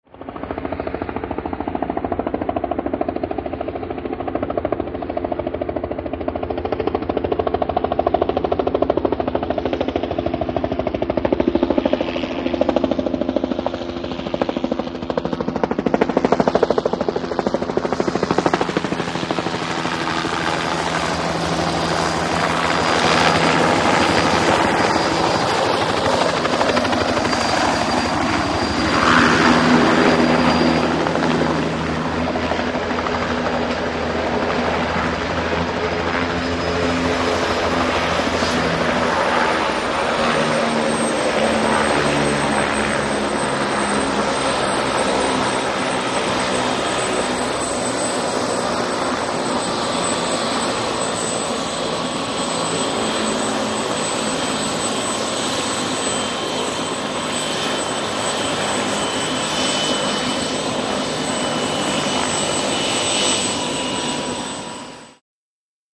HELICOPTER JET: EXT: Approach, by, landing, idle. Flight control bullhorn at end.